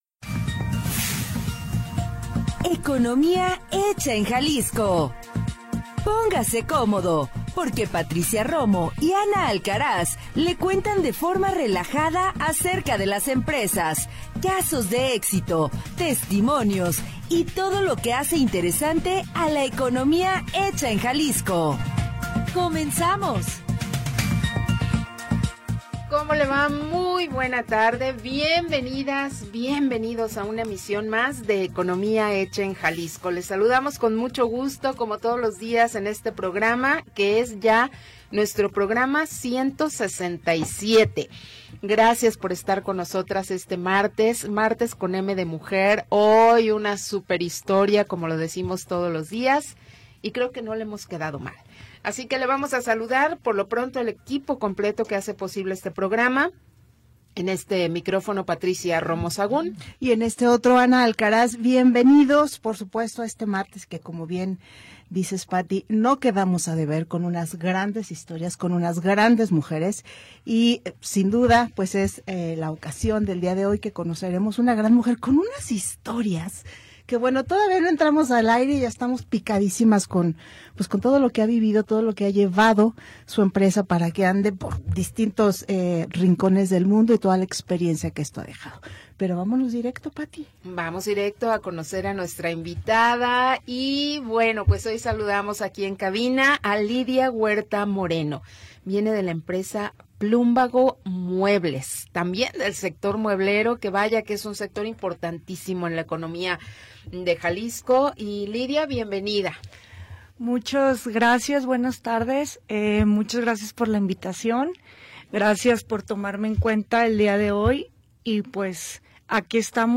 Programa transmitido el 17 de Noviembre de 2025.